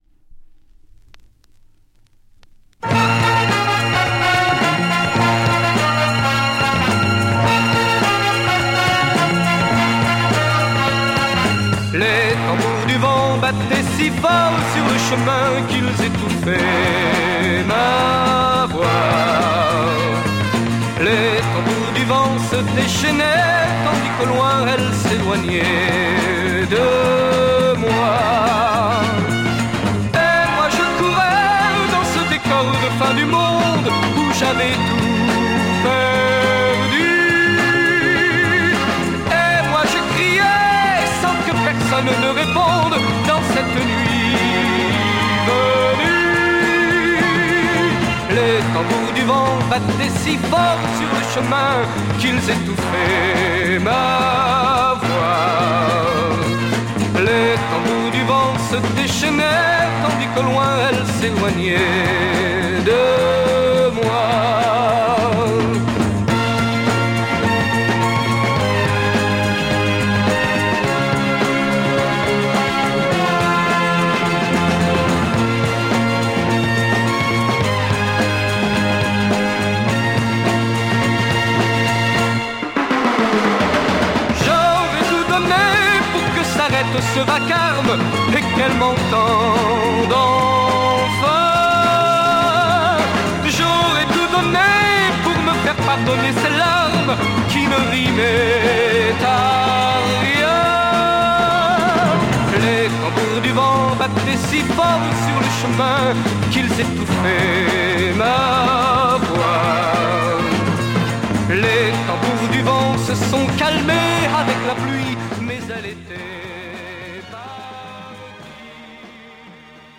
French Beaty Soul Mod dancer EP
Quite underestimated French Beat soul mod dancer!